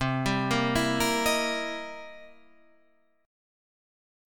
C7#9 chord